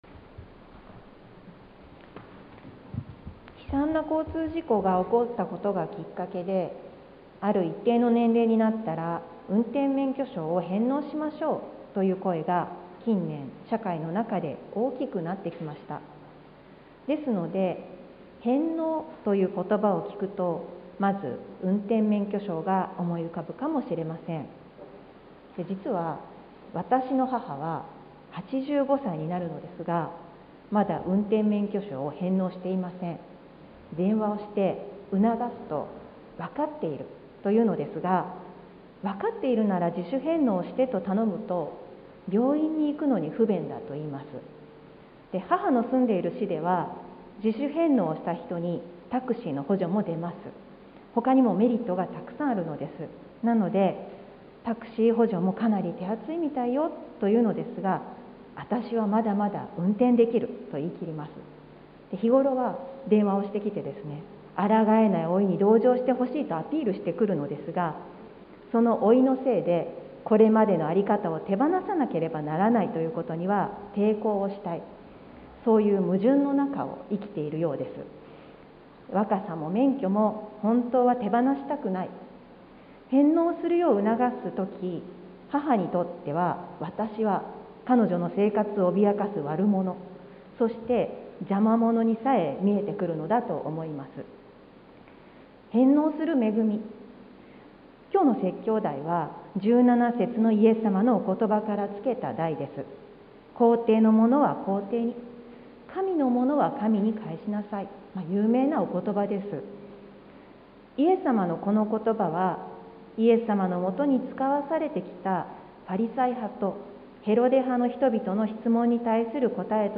sermon-2022-02-06